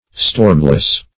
Stormless \Storm"less\, a.
stormless.mp3